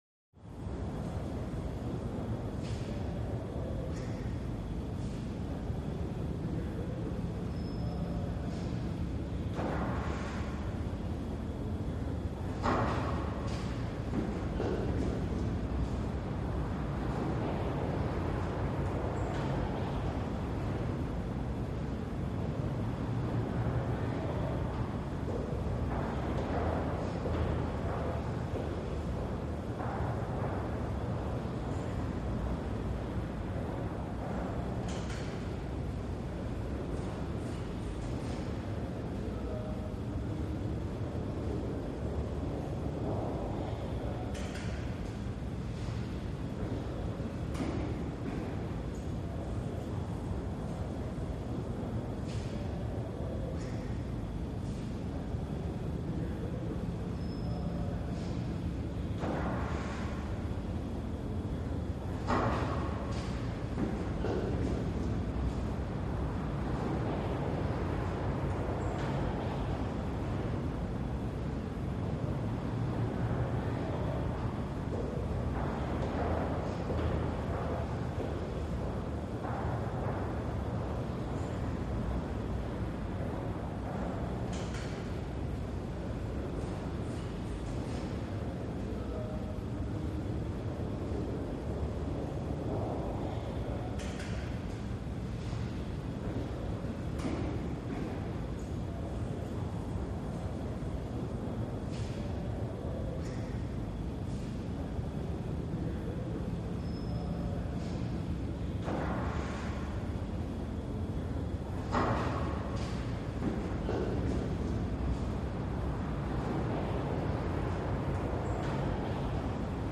Hallway - Large And Echoey